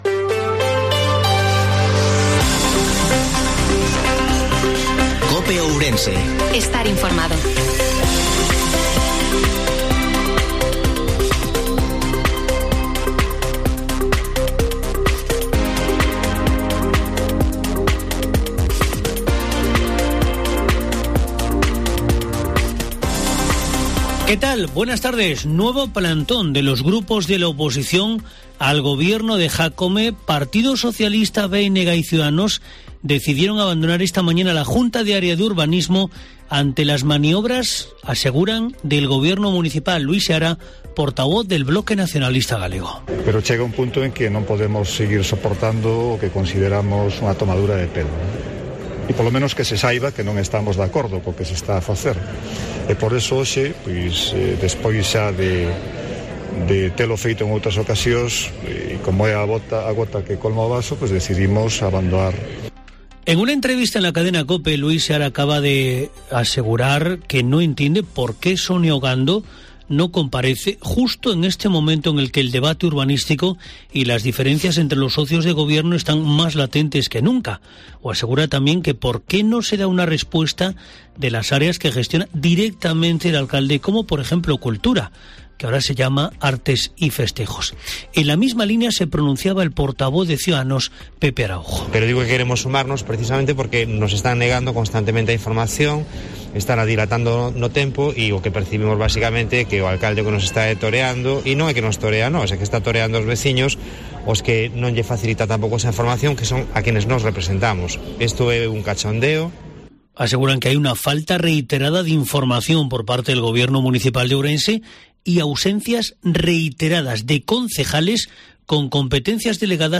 INFORMATIVO MEDIODIA COPE OURENSE 29/03/2022